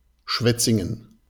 シュヴェツィンゲン (ドイツ語: Schwetzingen, ドイツ語発音: [ˈʃvɛt‿sɪŋən] (